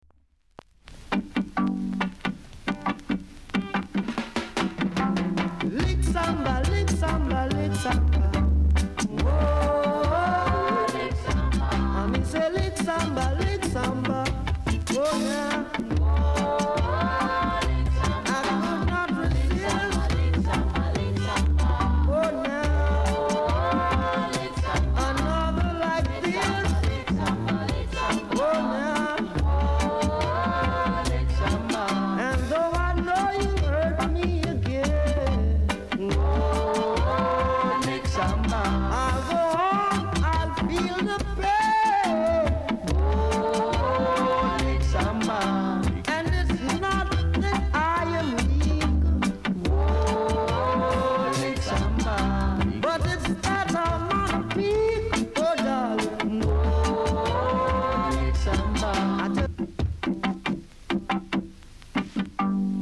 ※ジャマイカ盤と同じ様なかんじで、薄くノイズは感じます。ほか小さなチリ、パチノイズが少しあります。